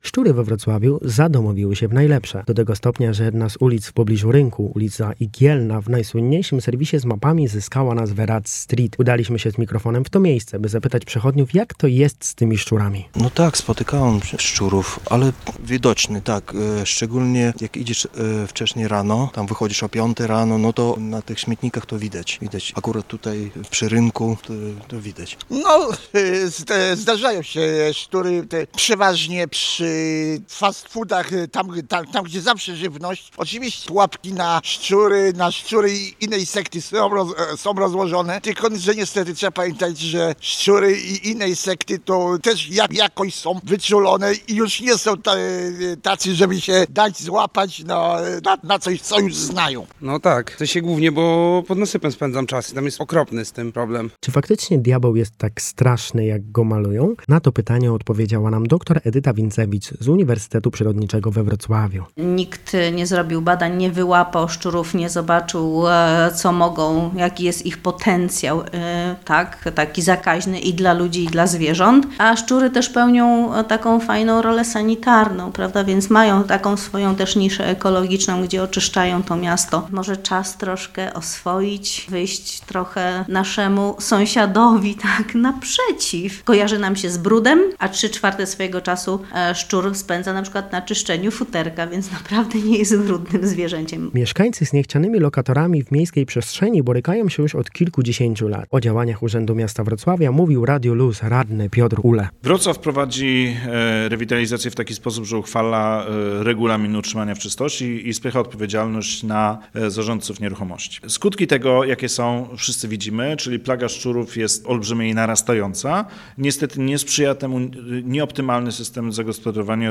W wywiadzie